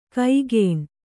♪ kaigēṇ